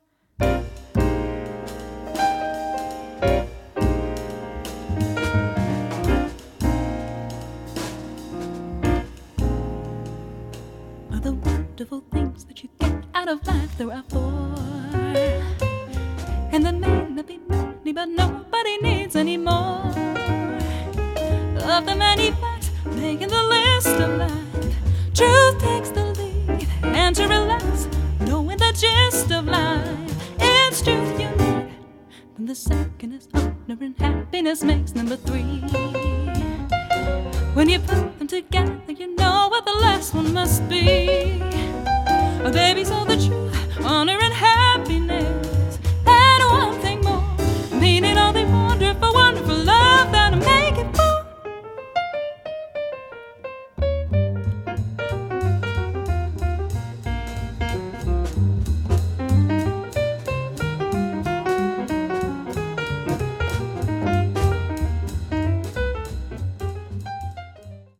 voc
piano
bass
drums